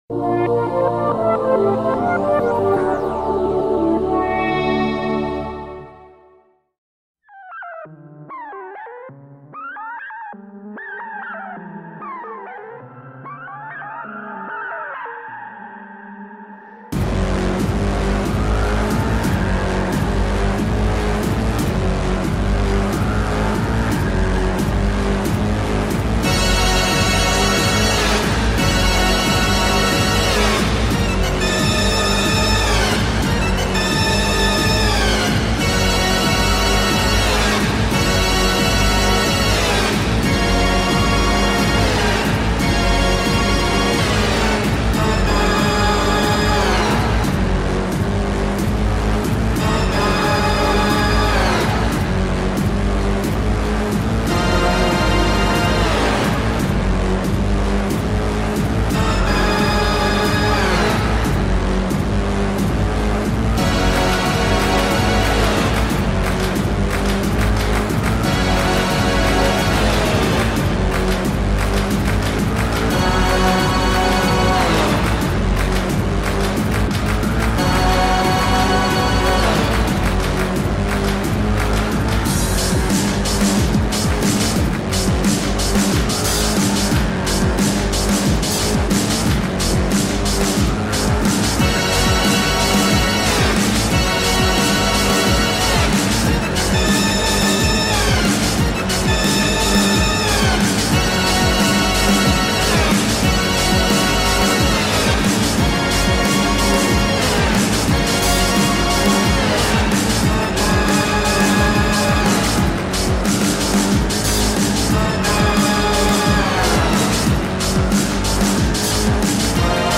Boss Battle
Live Synth Cover